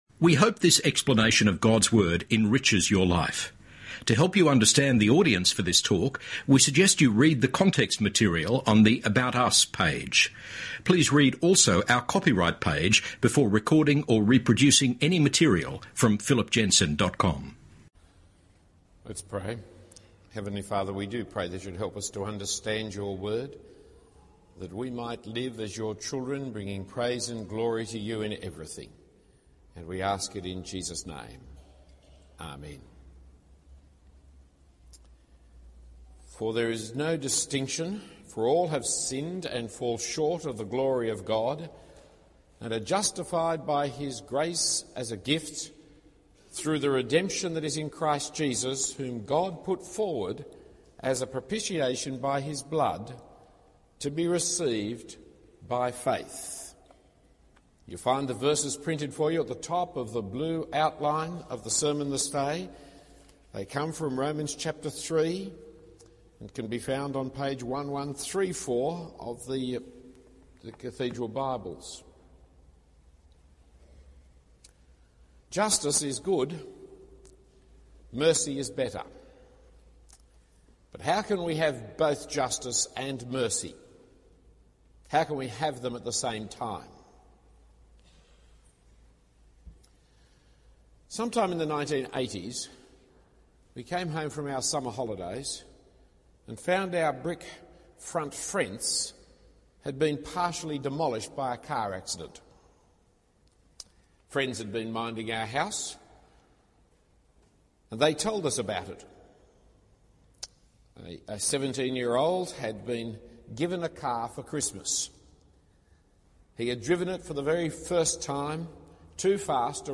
Talk 11 of 19
St Andrew's Cathedral